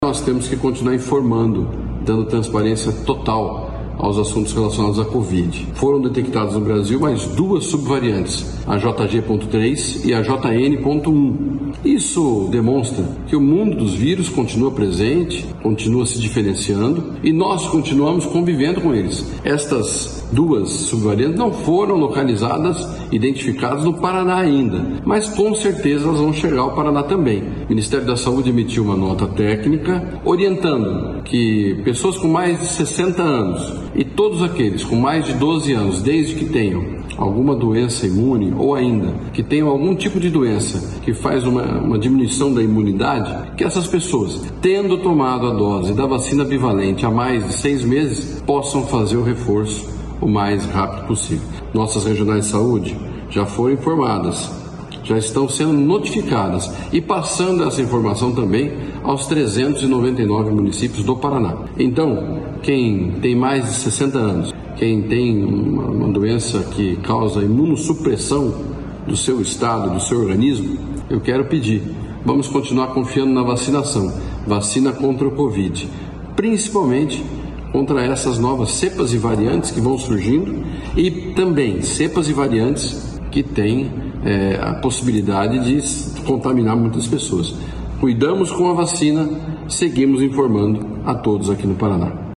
Sonora do secretário da Saúde, Beto Preto, sobre a ampliação da vacinação da bivalente contra Covid-19 para grupos prioritários